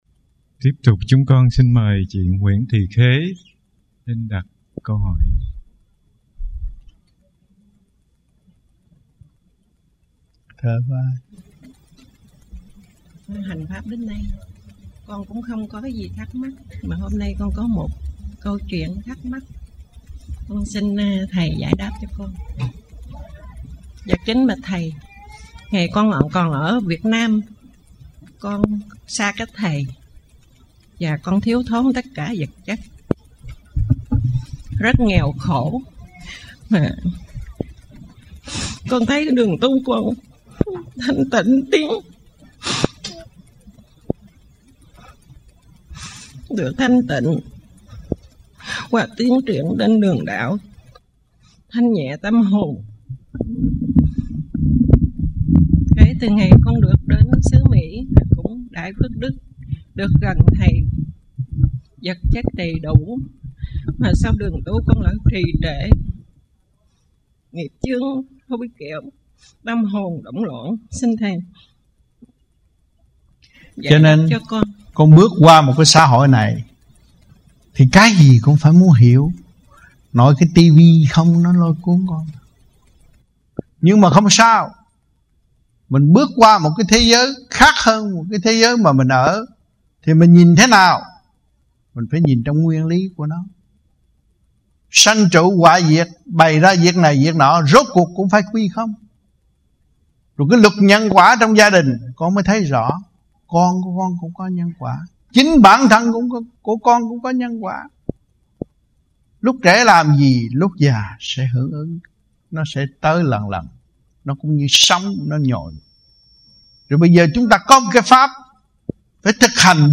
1989-02-19 - STANTON - LUẬN ĐẠO 2